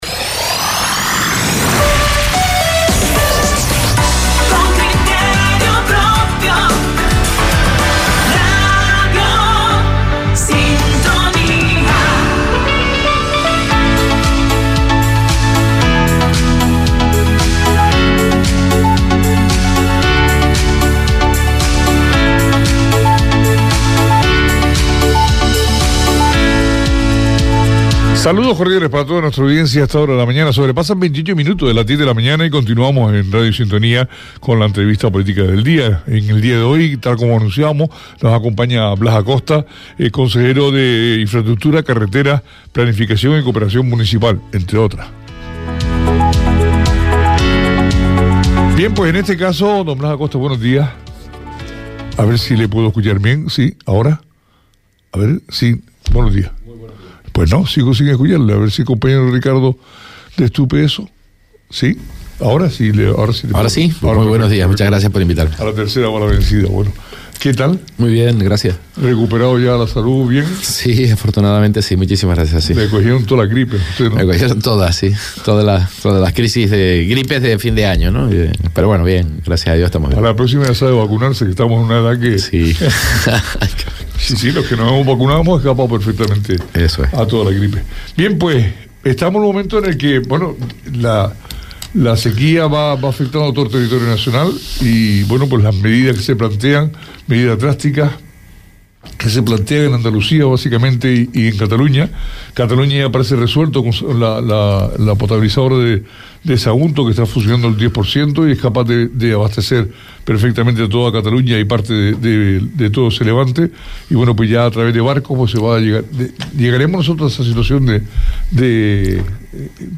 Entrevista a Blas Acosta, consejero de Infraestructuras, Carreteras, Planificación, y Cooperación Municipal - 06.02.24 - Radio Sintonía
Entrevista a Blas Acosta, consejero de Infraestructuras, Carreteras, Planificación, y Cooperación Municipal – 06.02.24